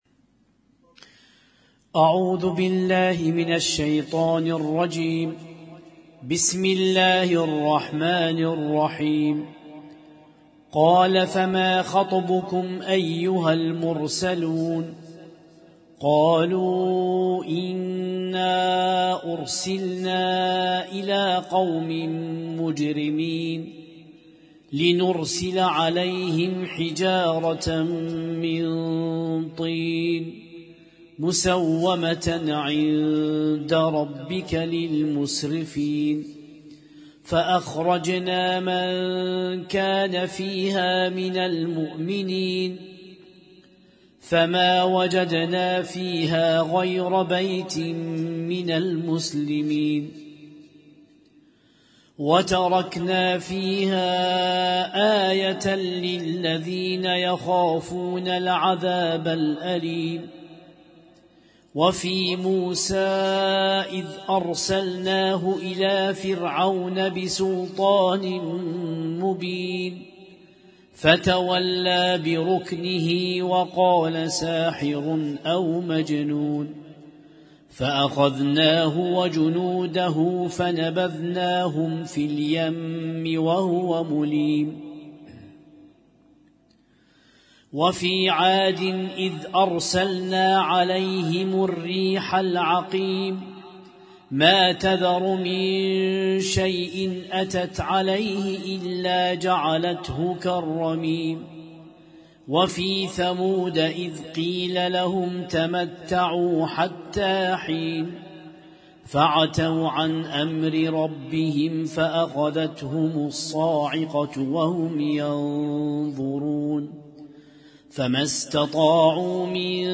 قائمة المـكتبة الصــوتيه القرآن الكريم الجزء 27